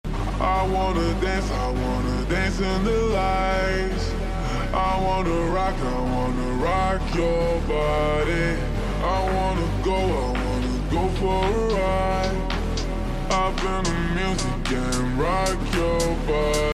Hyundai Elantra N Or Civic Sound Effects Free Download